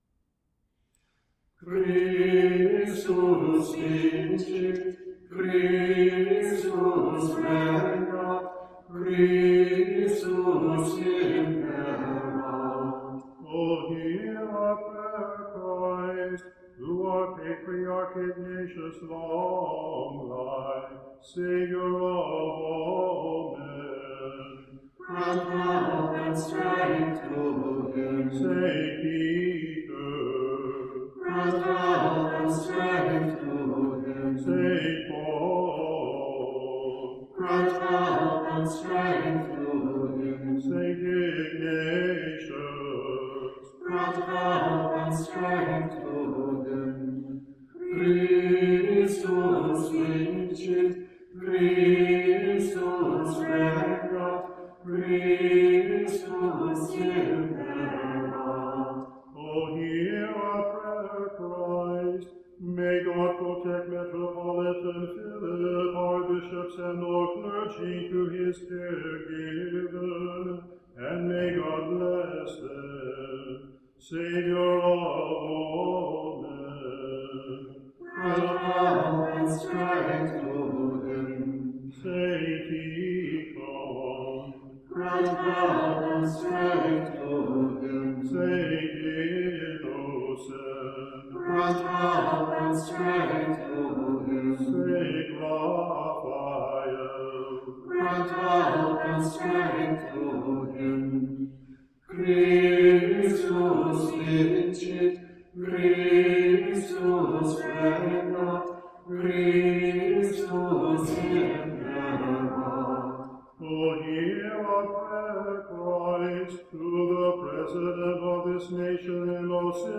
A few selections recorded by our choir (pieces marked with an * are from a CD recorded by singers from several Western Rite parishes).